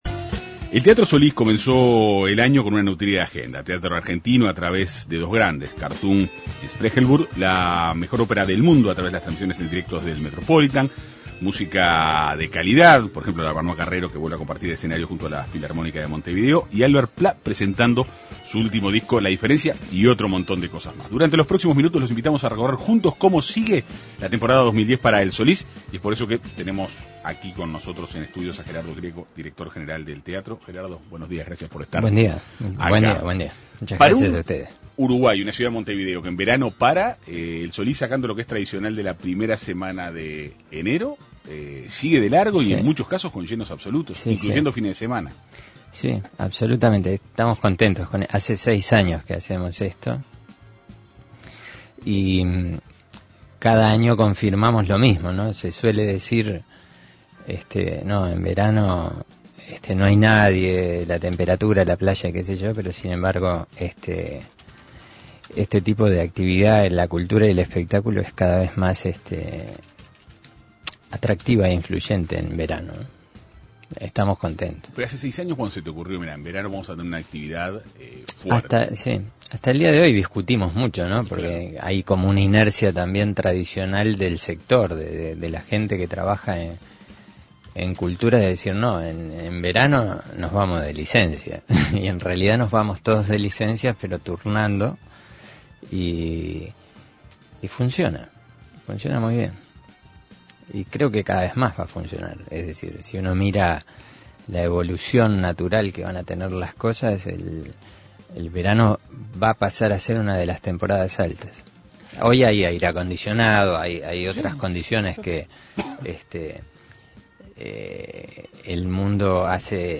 fue entrevistado por la Segunda Mañana de En Perspectiva.